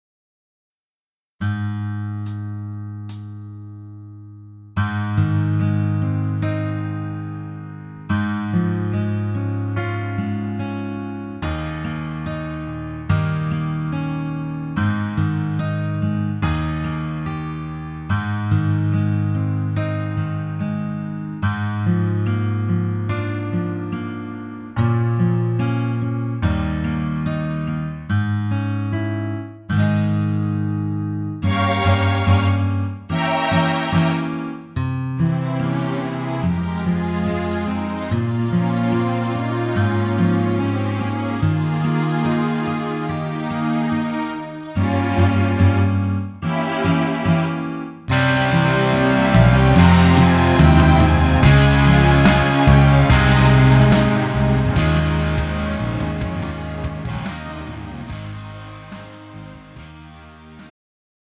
음정 원키 3:59
장르 가요 구분 Pro MR